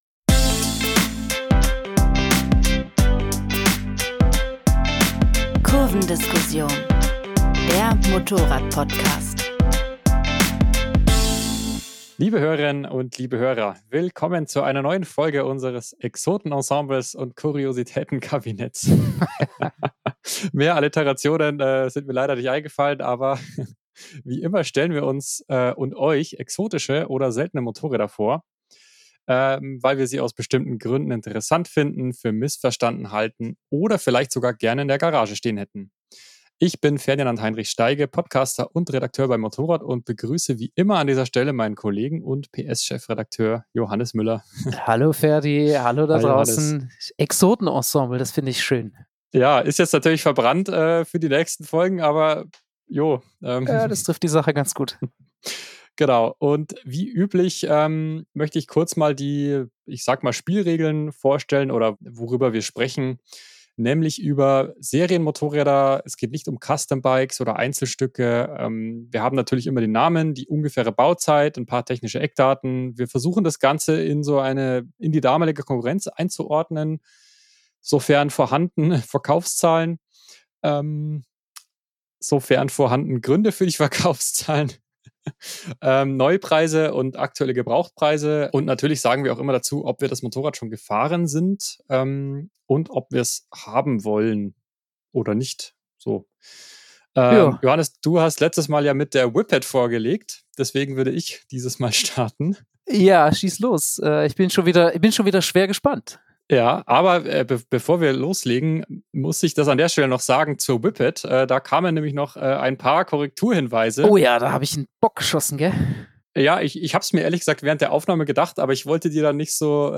Kurvendiskussion, das sind Benzingespräche am MOTORRAD-Stammtisch, mit Redakteuren und Testern. Es geht um aktuelle Modelle, Trends, Schrauberphilosophie und alles, was uns sonst bewegt.